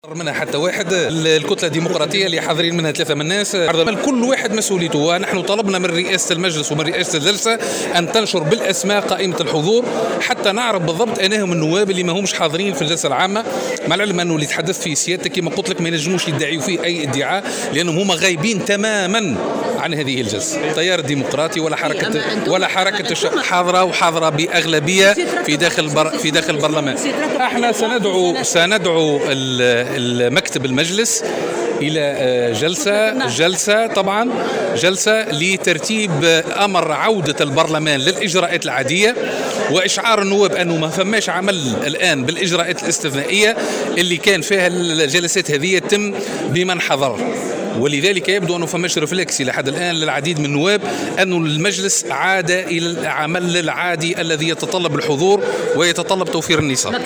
وأضاف في تصريح اليوم لمراسل "الجوهرة أف أم" أنهم طالبوا رئاسة مجلس النواب بنشر قائمة الحضور وتذكير النواب بأنه قد تم تعليق العمل بالاجراءات الاستثنائية واستئناف العمل بالاجراءات العادية التي تتطلب الحضور وتوفير النصاب، وفق قوله.